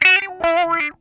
OUCH LICK 2.wav